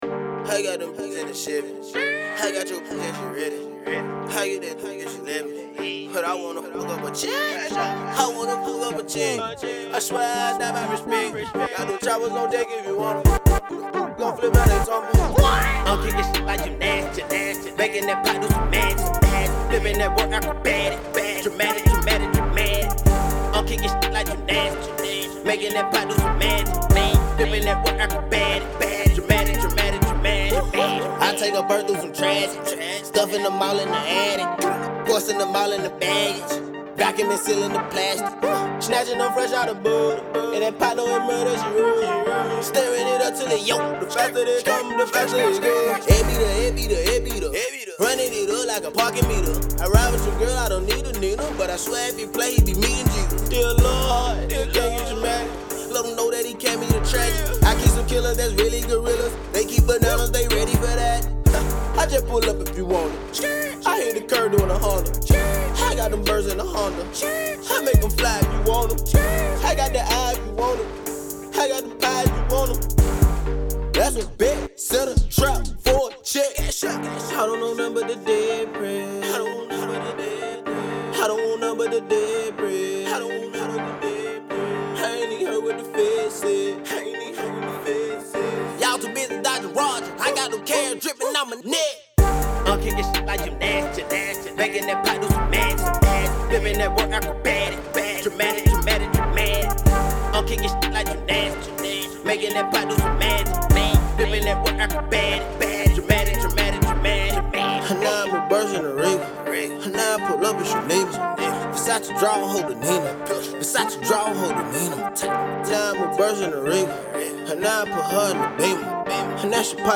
rap recording artist and songwriter